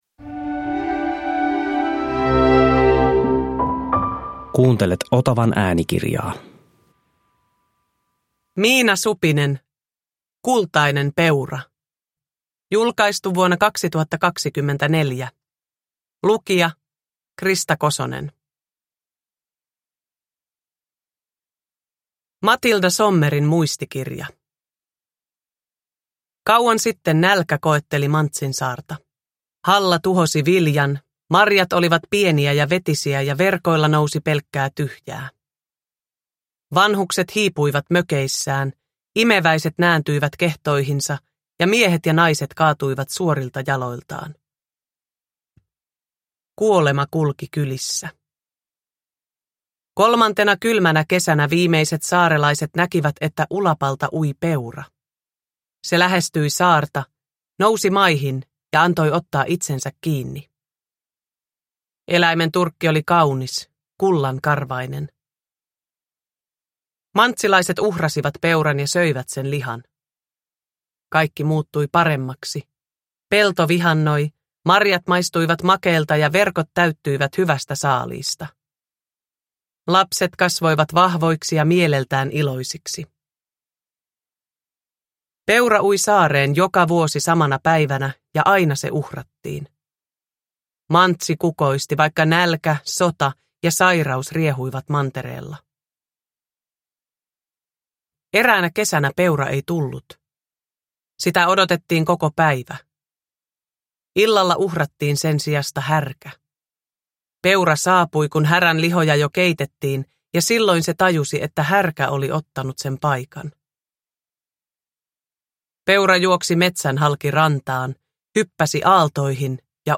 Kultainen peura – Ljudbok
Uppläsare: Krista Kosonen